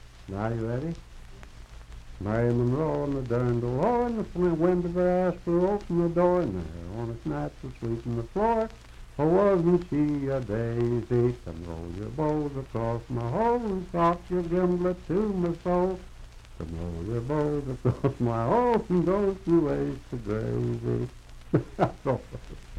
Unaccompanied vocal music
Voice (sung)
Fairview (Marion County, W. Va.), Marion County (W. Va.)